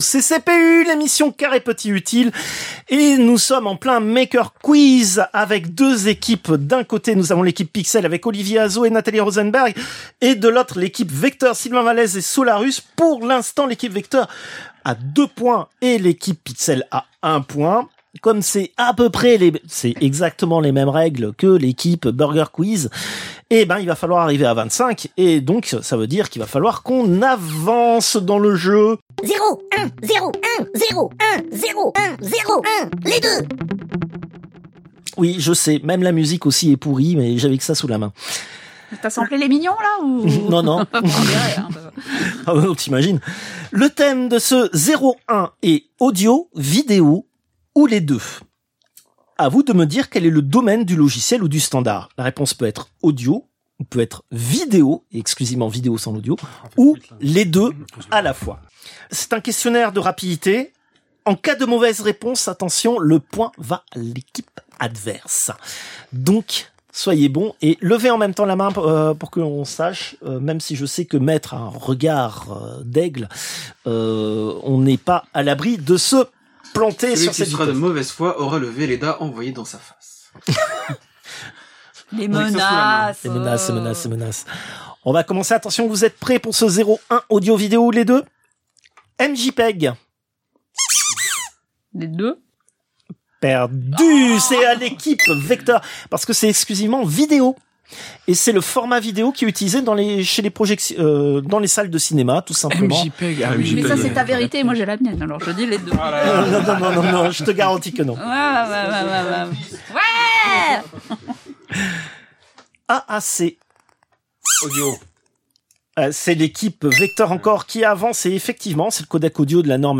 Extrait de l'émission CPU release Ex0227 : lost + found (un quart null).
[Jingle] zero ! un ! zero ! un ! LES DEUX !